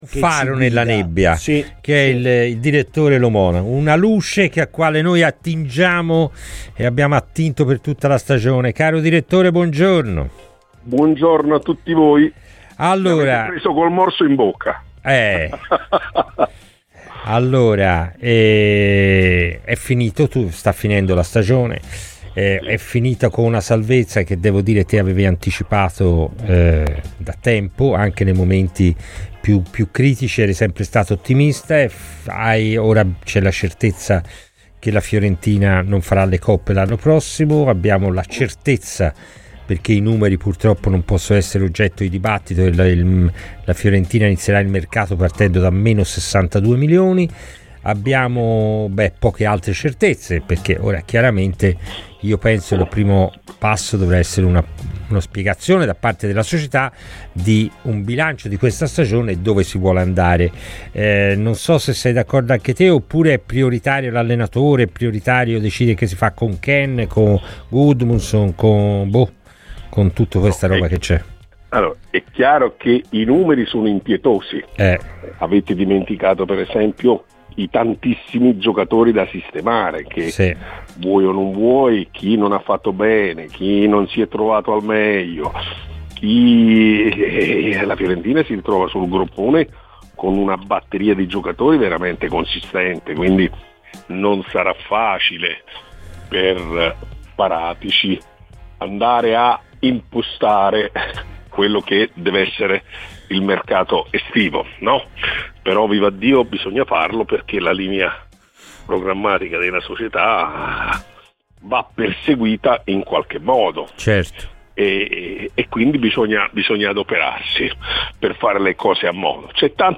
Ascolta l'intervista completa su Radio FirenzeViola!